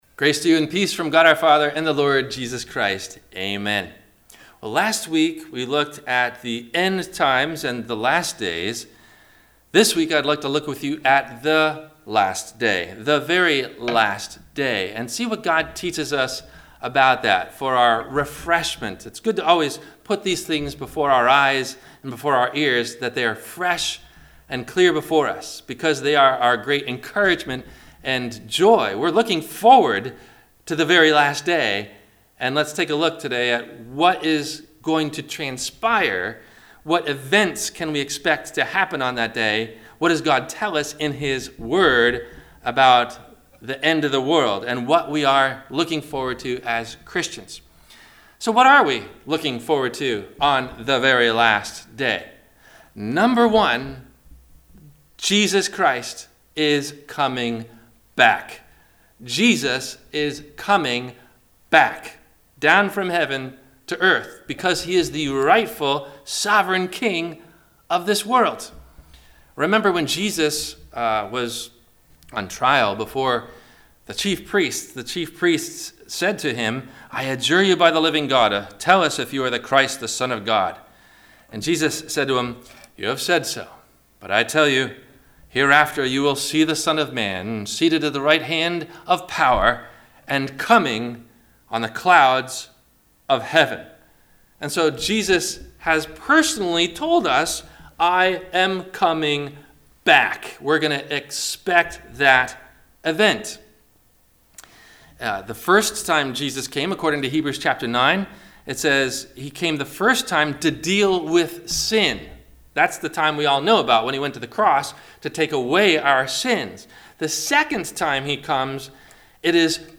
What Will Happen On The Last Day? – Corona Wk 6 – Entire Church Service – April 26 2020 - Christ Lutheran Cape Canaveral
NOTE: DUE TO THE CORONA V QUARANTINE, THE VIDEO BELOW IS THE ENTIRE CHURCH SERVICE AND NOT JUST THE SERMON AS IN THE PAST.